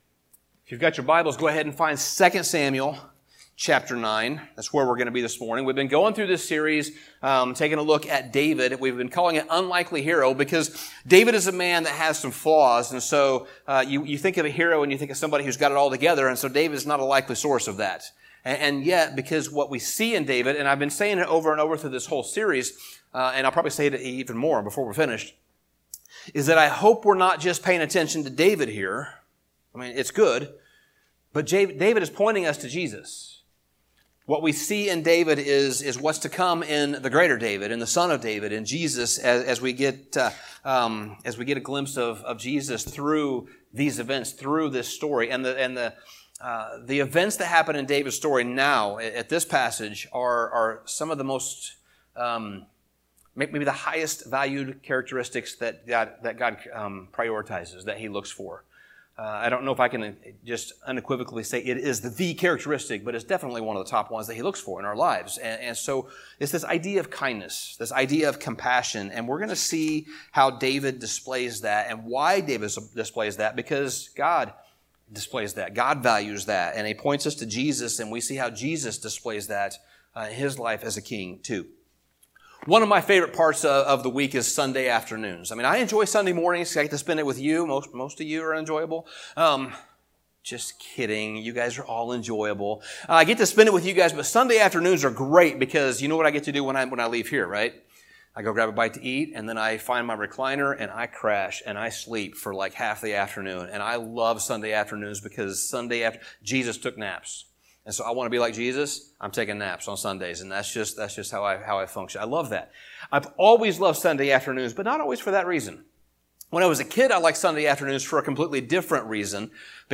Sermon Summary David is king.